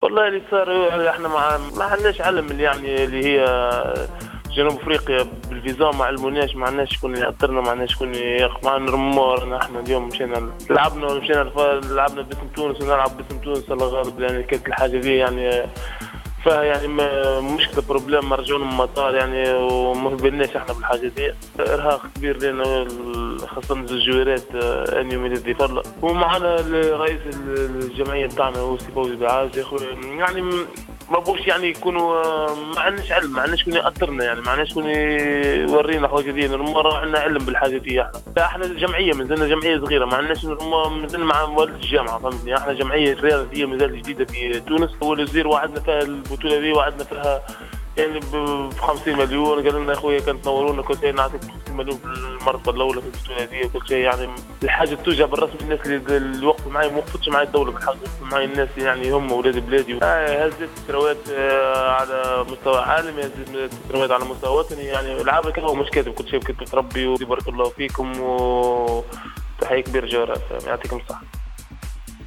تصريح لجوهرة اف ام